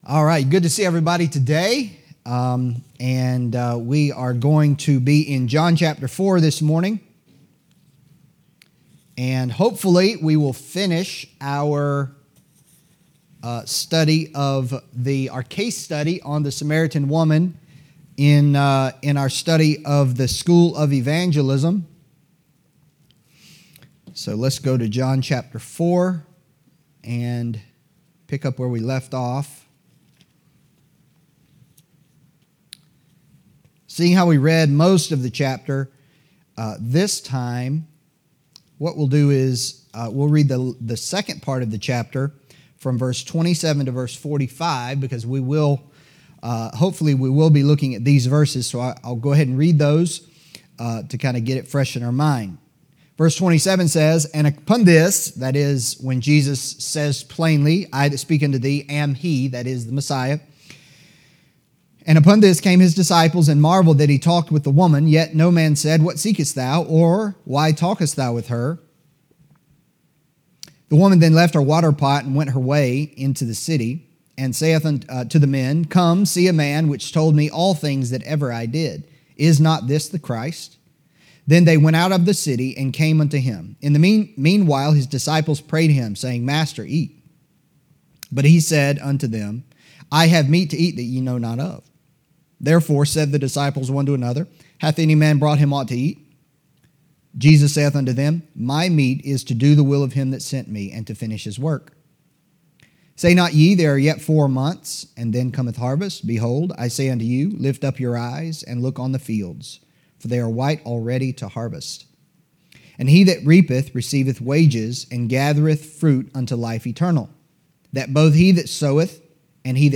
Adult Sunday School: School of Evangelism &middot